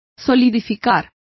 Complete with pronunciation of the translation of solidifies.